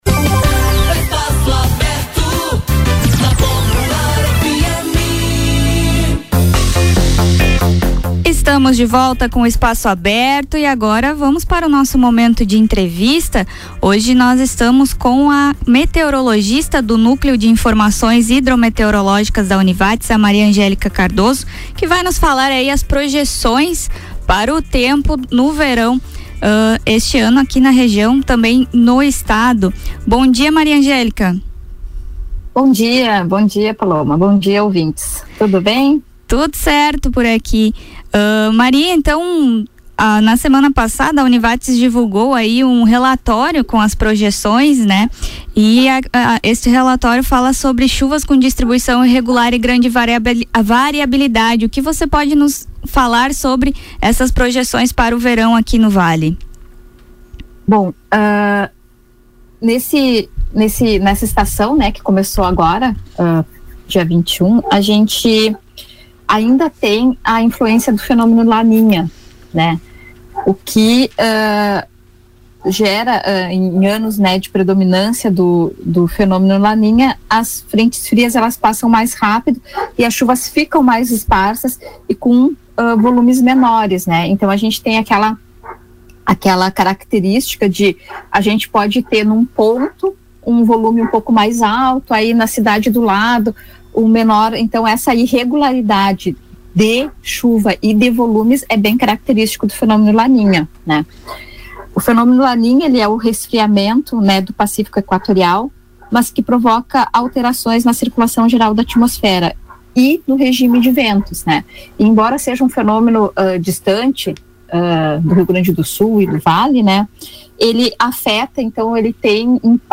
Em entrevista ao Espaço Aberto desta sexta-feira